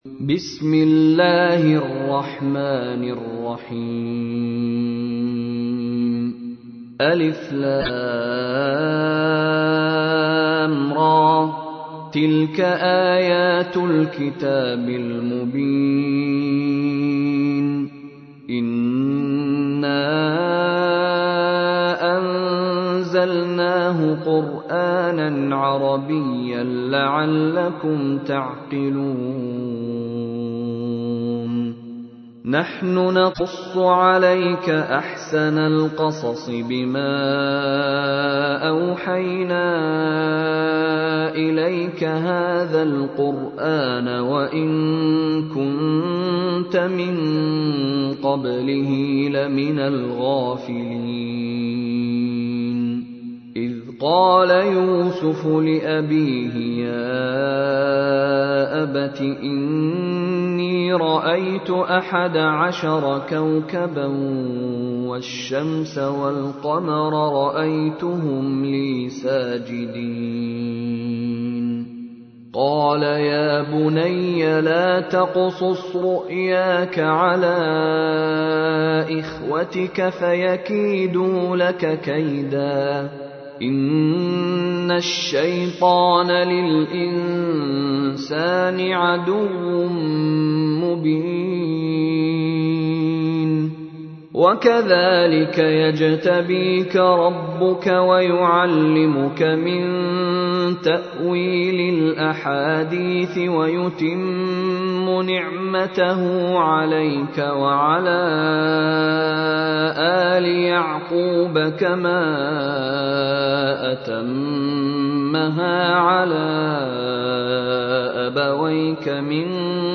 تحميل : 12. سورة يوسف / القارئ مشاري راشد العفاسي / القرآن الكريم / موقع يا حسين